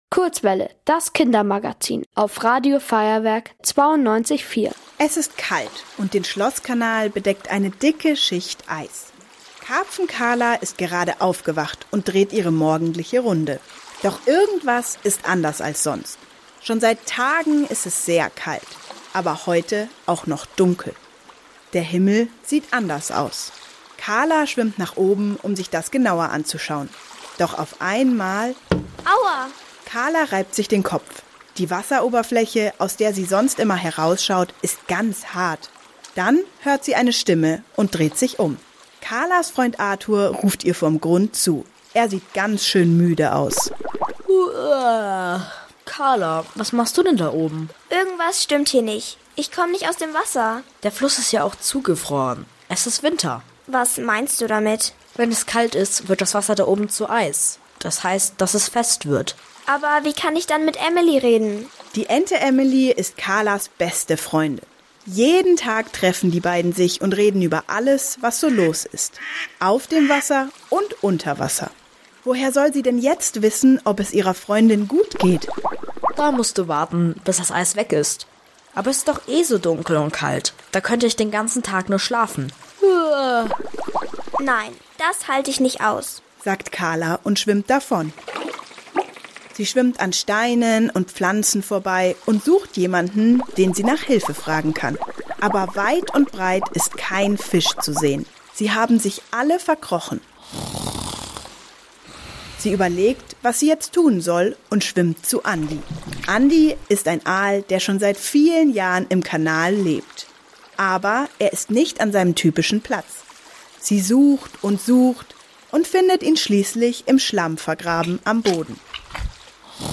Hörspiel: Fischwinter 6:16